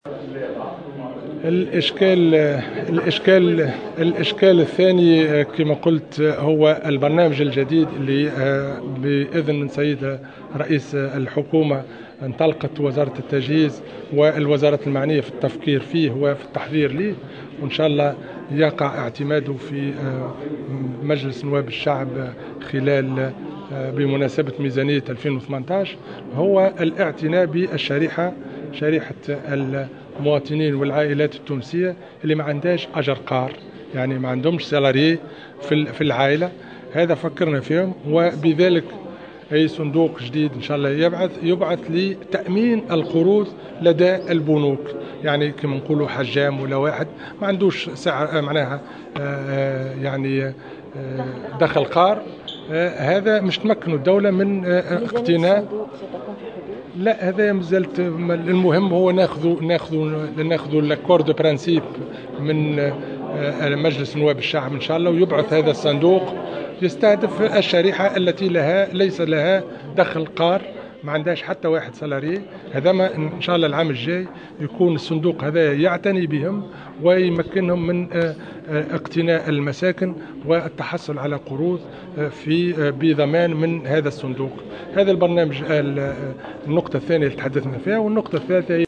وقال في تصريح لـ "الجوهرة اف أم" إنه ينتظر اعتماد هذا المشروع في ميزانية 2018 ويستهدف الأشخاص الذين لا يتمتعون بدخل قار من أصحاب المهن الحرة الصغرى.